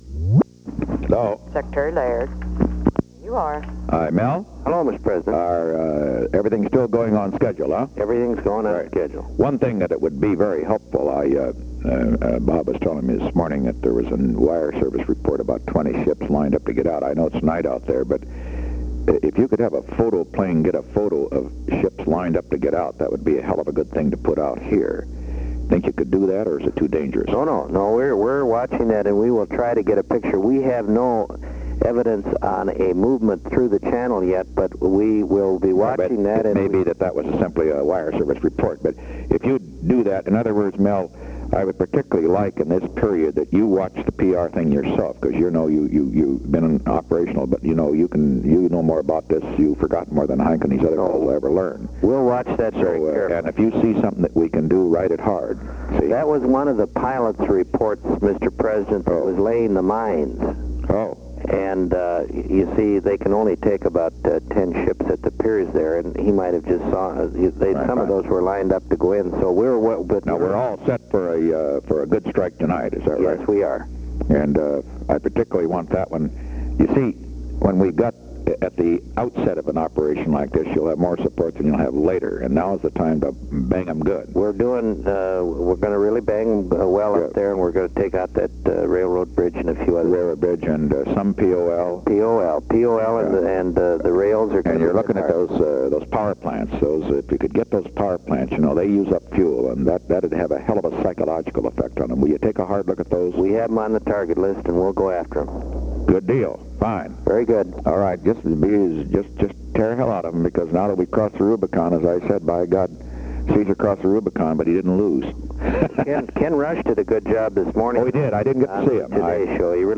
Secret White House Tapes
Conversation No. 24-40
Location: White House Telephone
The President talked with Melvin R. Laird.